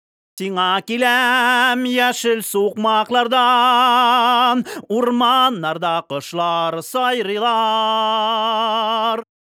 "Зудящий" шум в вокале - откуда?
Недавно записываю довольно громкий голос и бац, в вокале где-то на средне-высоких и/или высоких частотах (где-то от 4 кГц и выше) очень неприятный шум, который можно сравнить с зудом - вокал "зудит", как будто дисторшна или биткраша на верха слегка навалили.